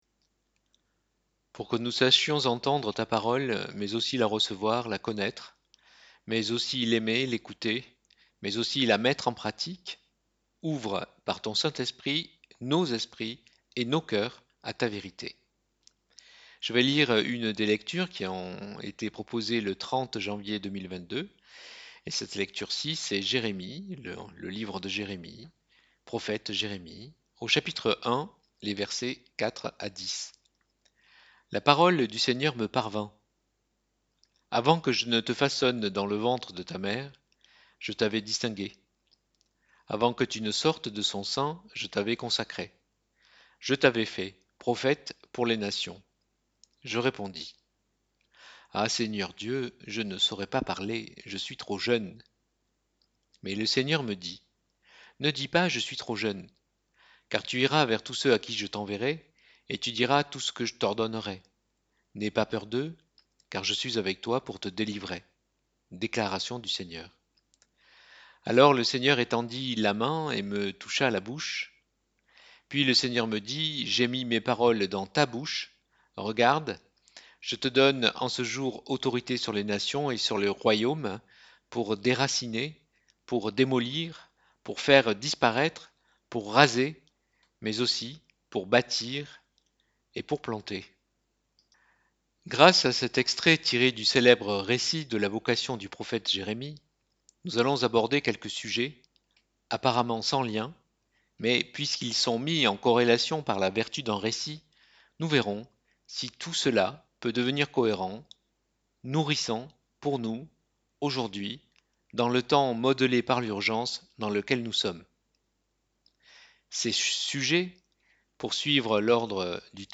Prédication du 30 janvier 2022.mp3 (27.4 Mo)